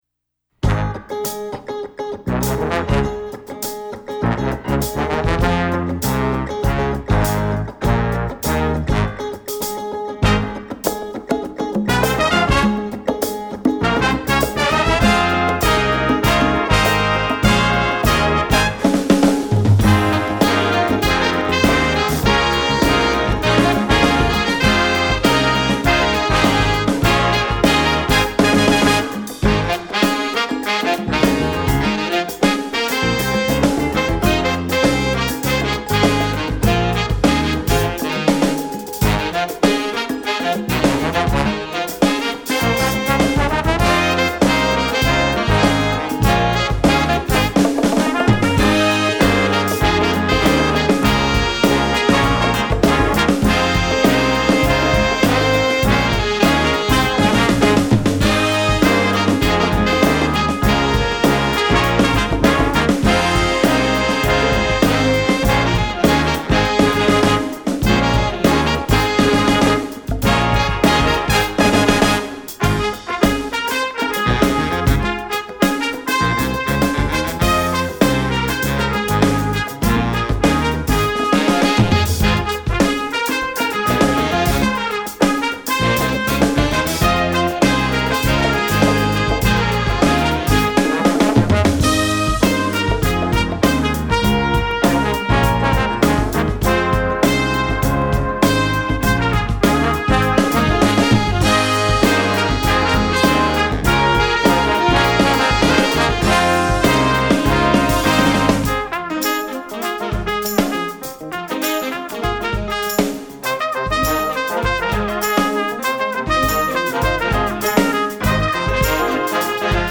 Besetzung: Jazz-Ensemble
in einem funkigen Arrangement
Geschickt für die gesamte Band arrangiert.